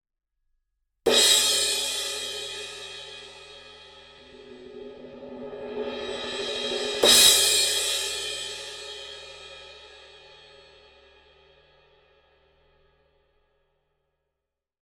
Тарелка crash rock 18